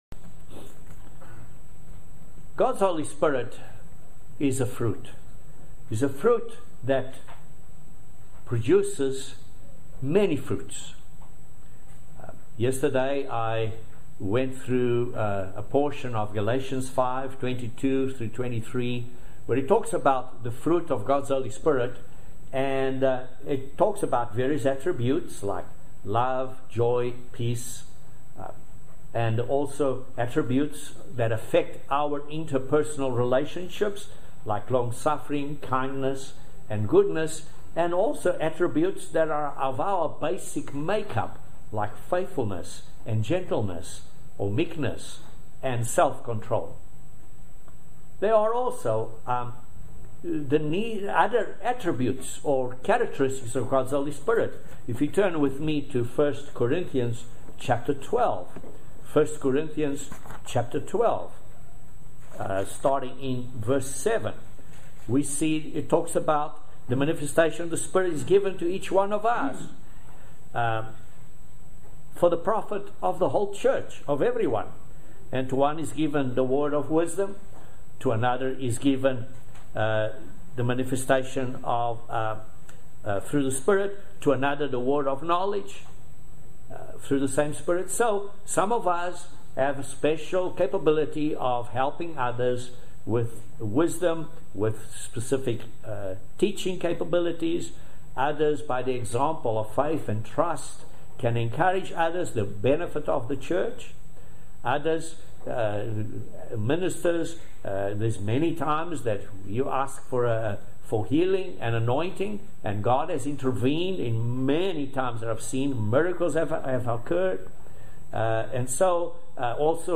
Join us for this very exciting video sermon. God's Holy Spirit , guides, begets, and Sanctifies us.